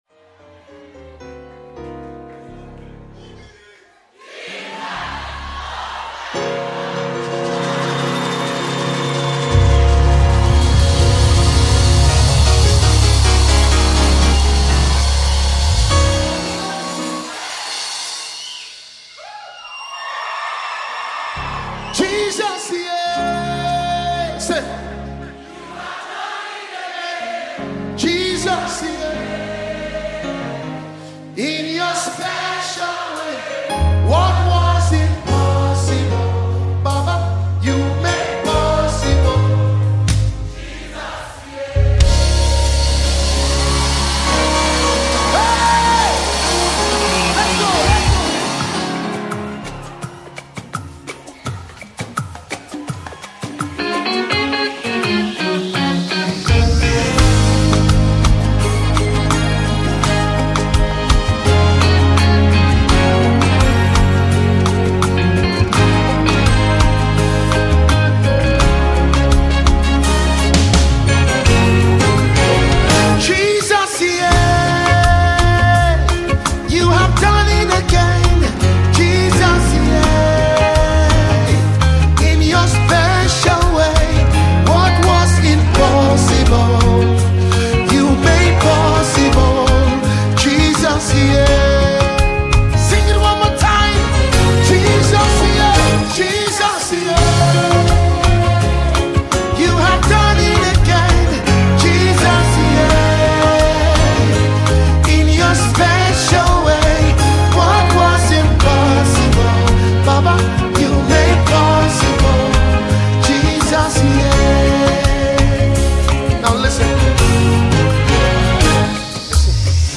a song of praise